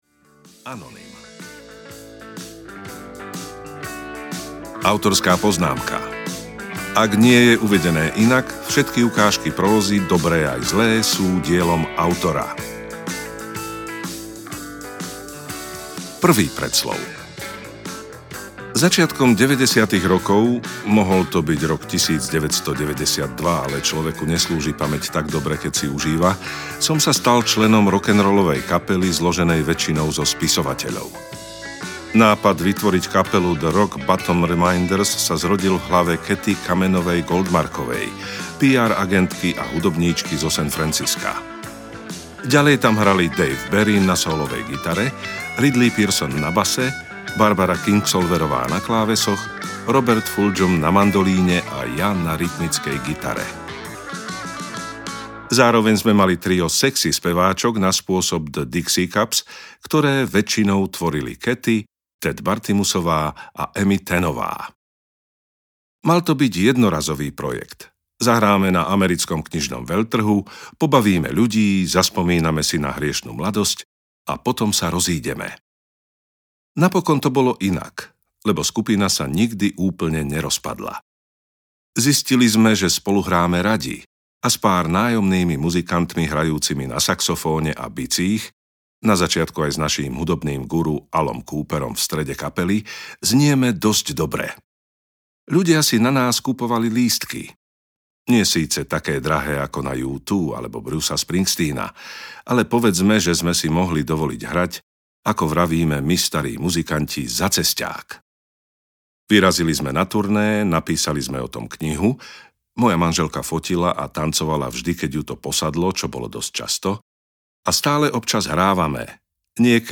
O písaní audiokniha
Ukázka z knihy